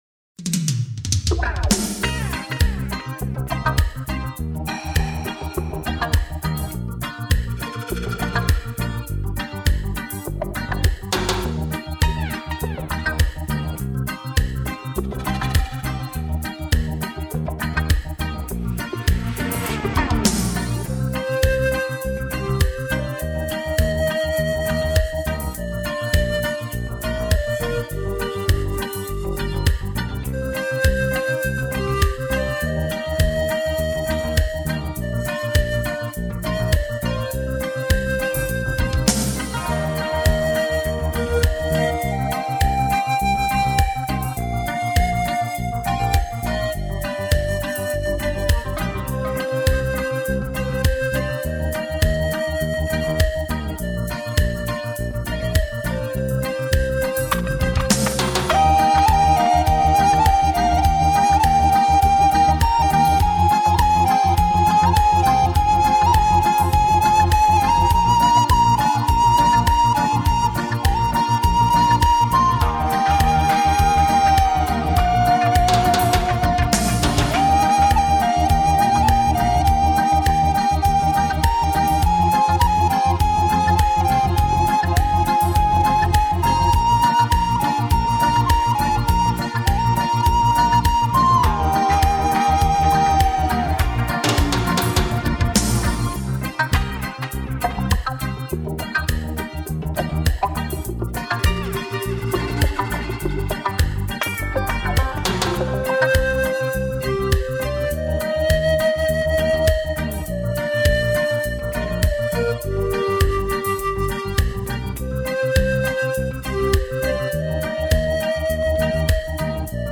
●录音制式：DDD STEREO
陶笛晶莹剔透的音色、排箫飘逸独特的风格和淳厚低沉的伴奏构成了这一独特的组合。
其悠闲、跳跃、随性的音乐风格以及清晰、饱满的录音让他成为通俗音乐之中的另类。